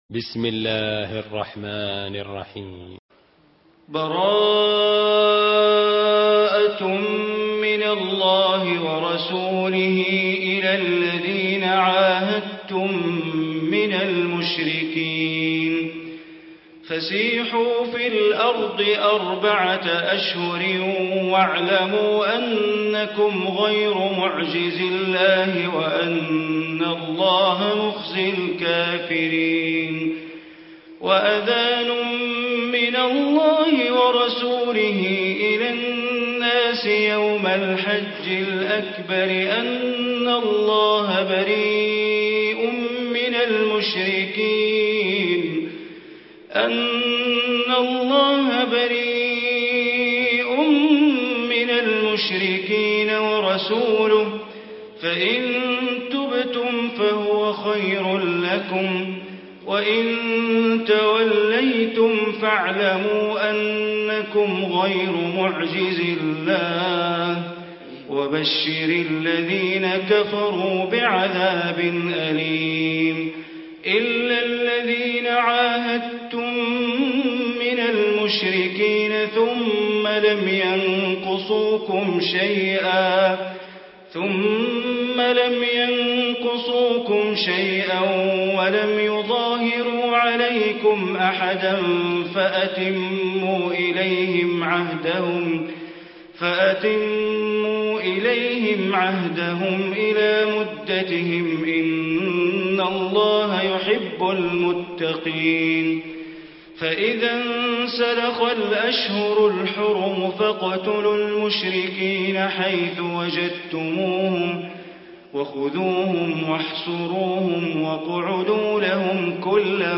Surah Taubah Recitation by Sheikh Bandar Baleela
Surah Taubah, listen online mp3 tilawat / recitation in Arabic in the beautiful voice of Imam e Kaaba Sheikh Bandar Baleela. Surah Taubah is 09 chapter of Holy Quran.